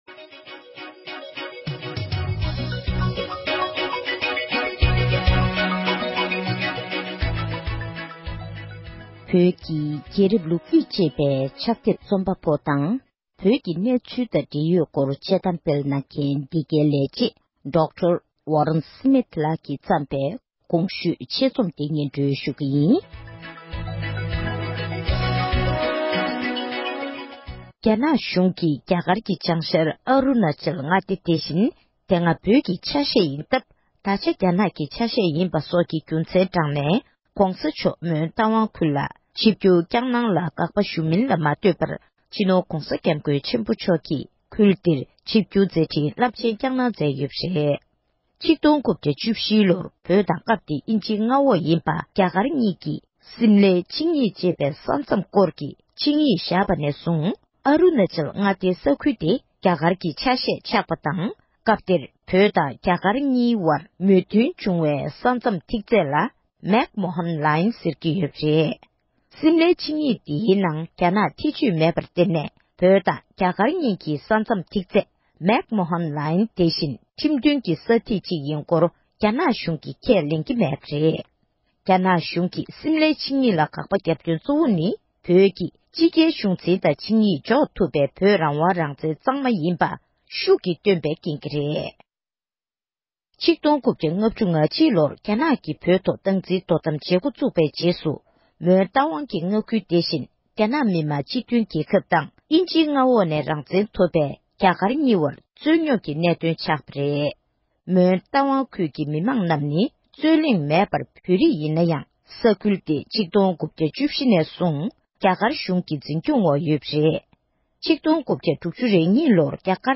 བོད་སྐད་ཐོག་ཕབ་བསྒྱུར་གྱིས་སྙན་སྒྲོན་ཞུས་པར་གསན་རོགས༎